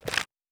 04_书店内_打开生死簿.wav